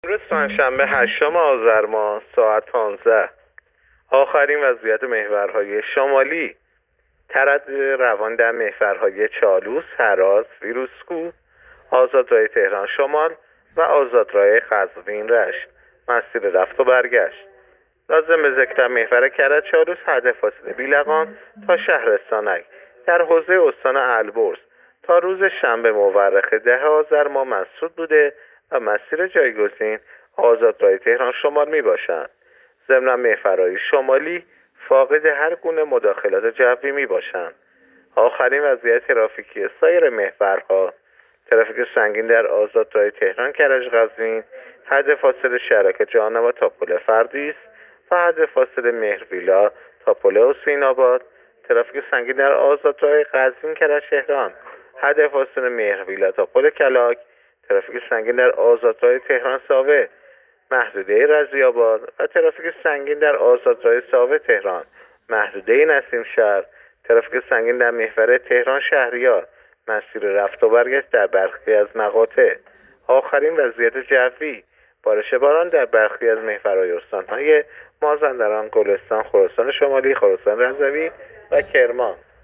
گزارش رادیو اینترنتی از آخرین وضعیت ترافیکی جاده‌ها تا ساعت ۱۵ هشتم آذر؛